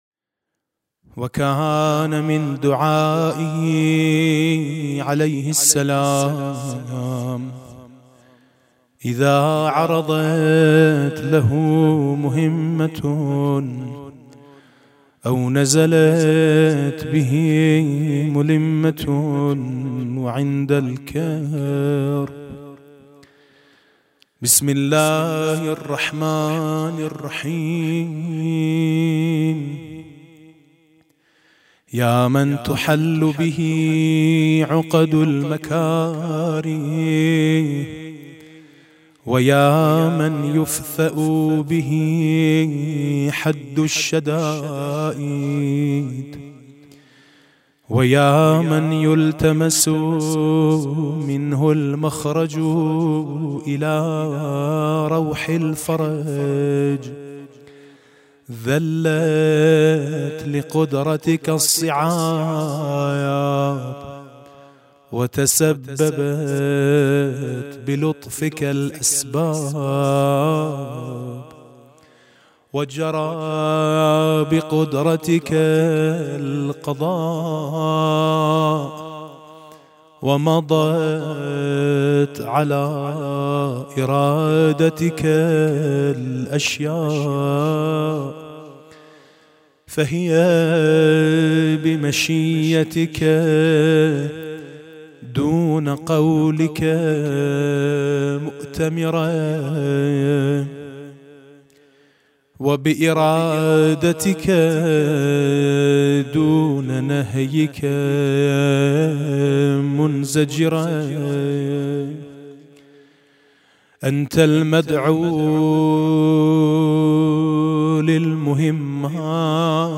دعا و زیارت: دعای هفتم صحیفه سجادیه (لحن عربی) حاج میثم مطیعی